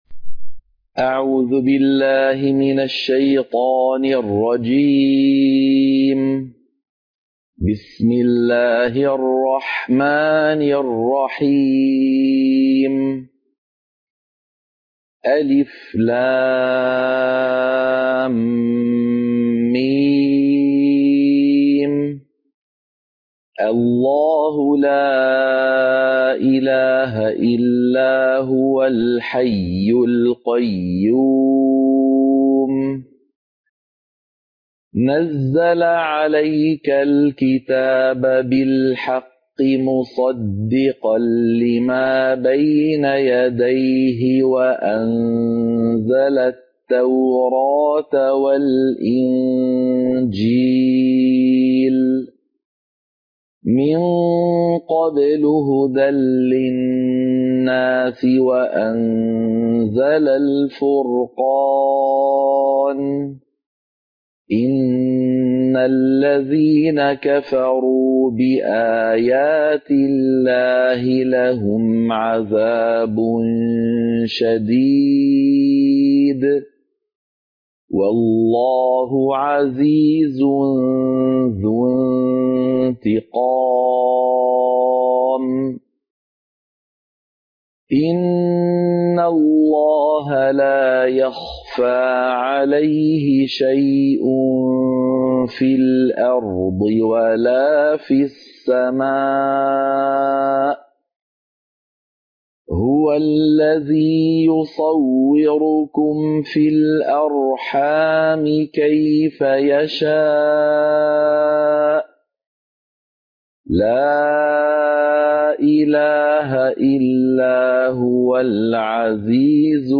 سورة آل عمران - القراءة المنهجية